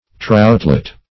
Troutlet \Trout"let\ (-l[e^]t), n. A little trout; a troutling.